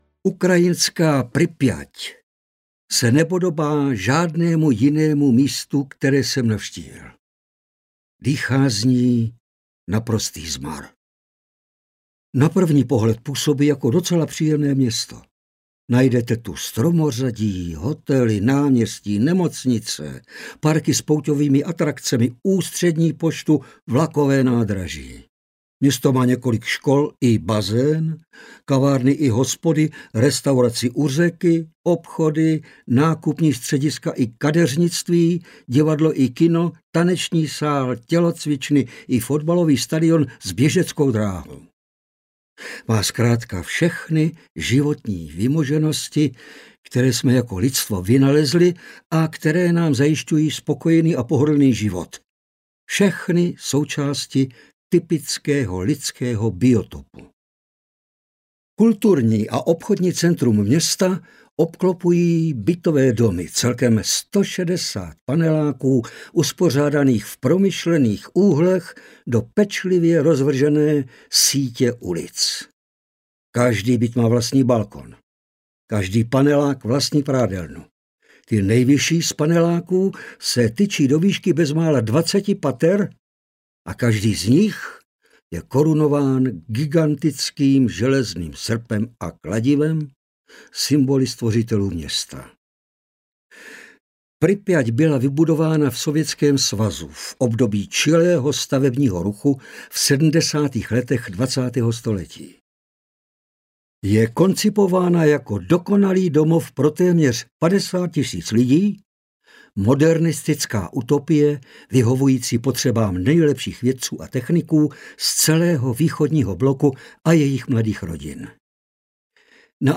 Audiokniha Život na naší planetě - David Attenborough | ProgresGuru
Čte: Ladislav Frej